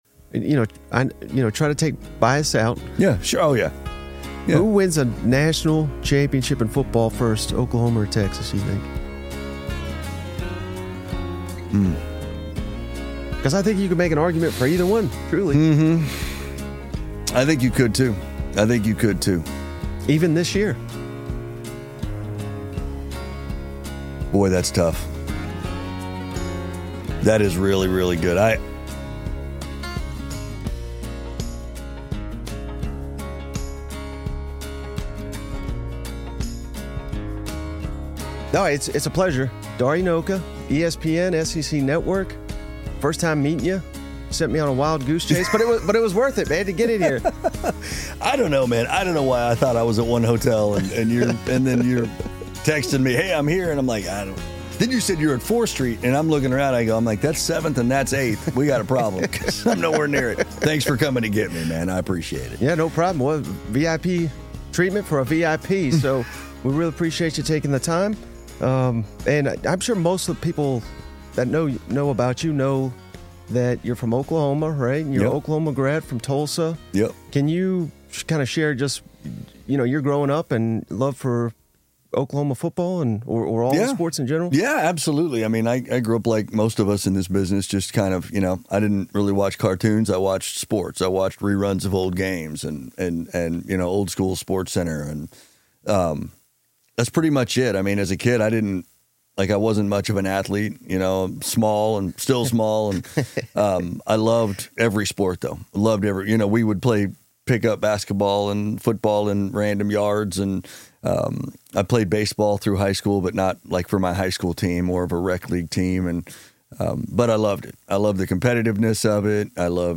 Who Wins National Championship First: Oklahoma or Texas? Dari Nowkhah In Studio from That SEC Football Podcast | Podcast Episode on Podbay